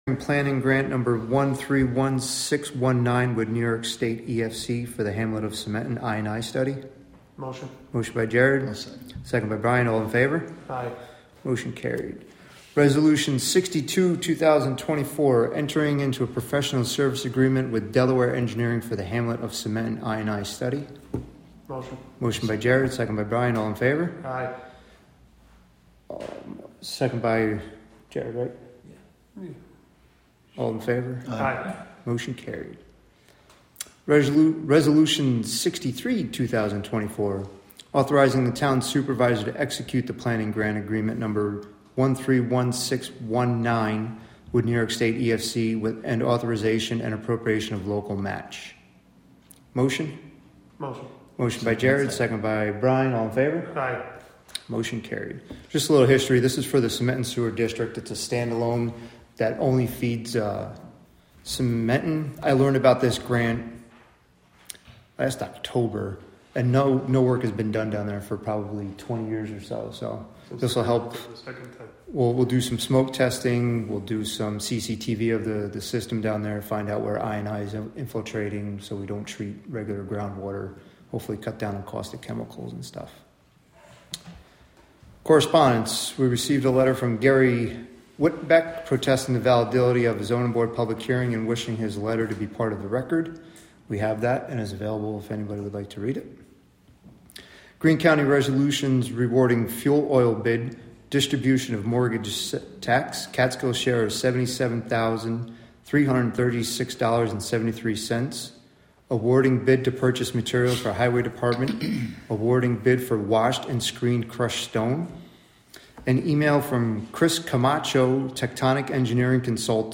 Live from the Town of Catskill: June 4, 2024 Catskill Town Board Meeting (Audio)